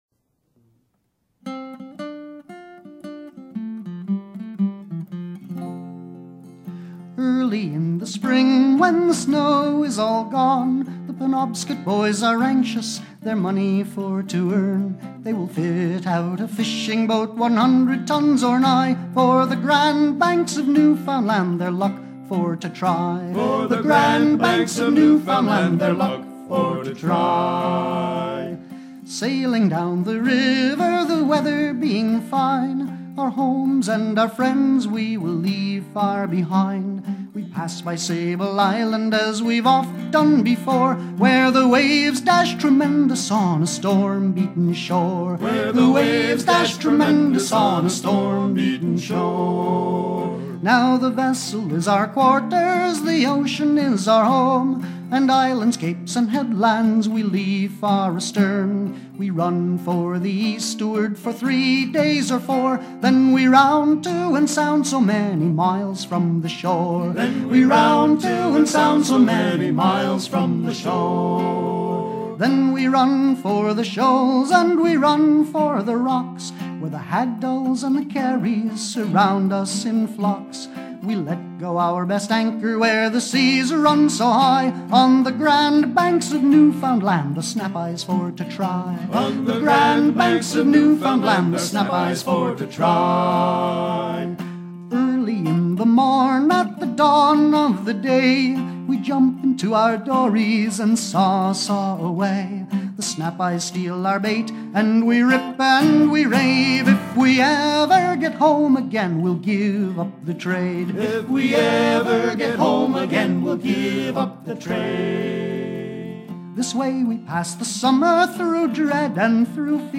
circonstance : maritimes
Genre strophique
Pièce musicale éditée